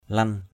/lʌn/ 1.